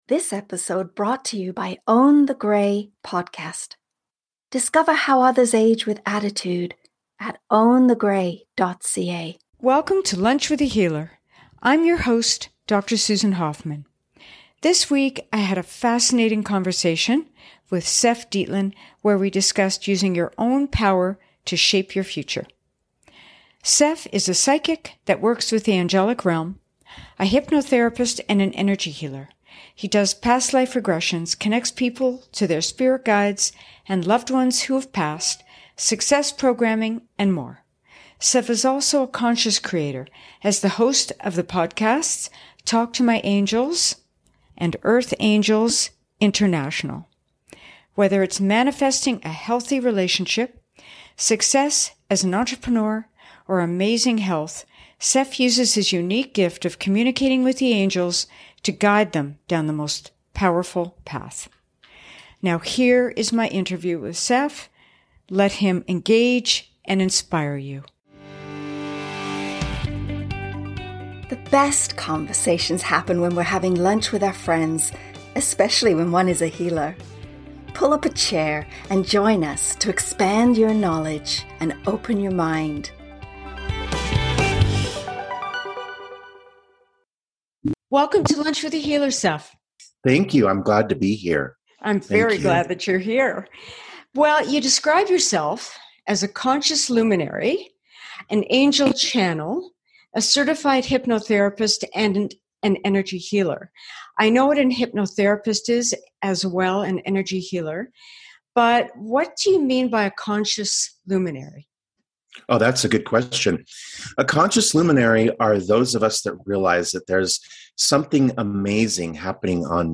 There are 3 Keys to Alignment which are necessary to be able to shape our day to day life and manifest our dreams: 1) Awake and Connect- to our higher consciousness 2) Self-Love- this is essential for sustained change in your life 3) Collapsing Limiting Beliefs- and Inviting in New Infinite Beliefs This is an inspiring and fun conversation- and remember.